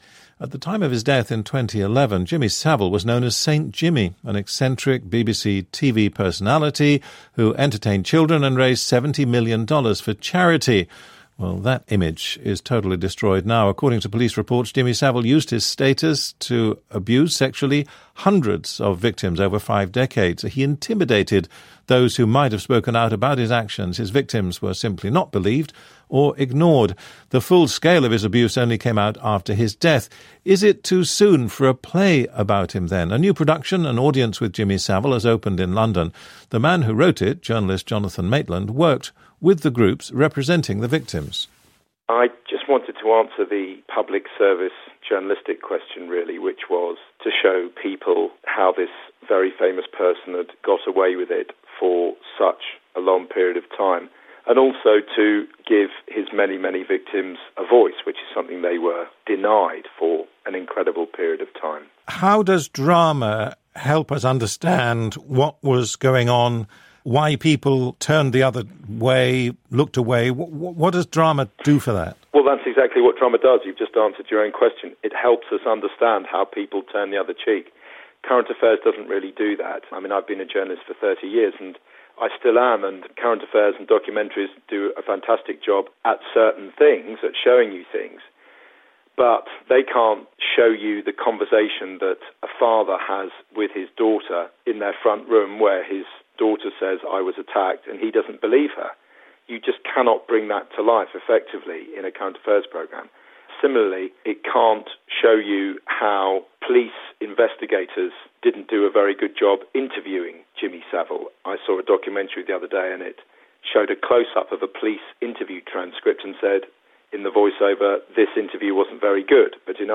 BBC World Service 15/6/15 - Jonathan Maitland interview about Savile play and CSA today.